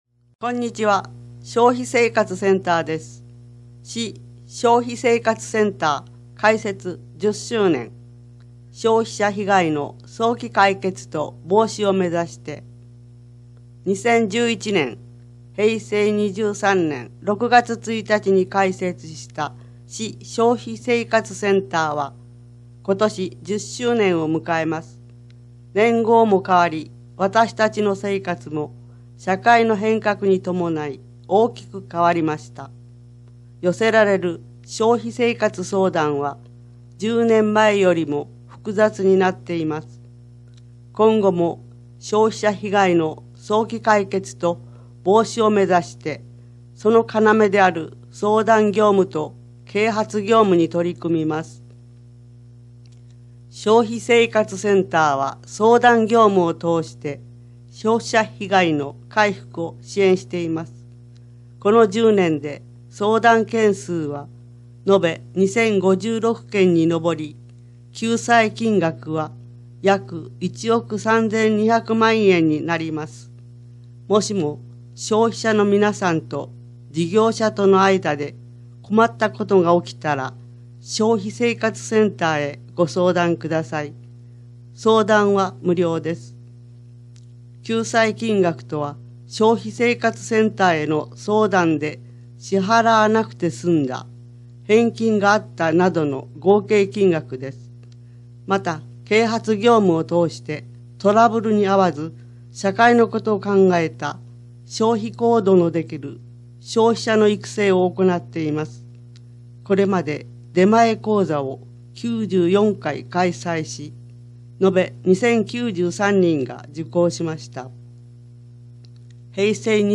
音訳（※声の広報紙）
この音訳データは、音訳ボランティア「うぐいすの会」の皆さんにより作成された音読データをmp3形式で提供しています。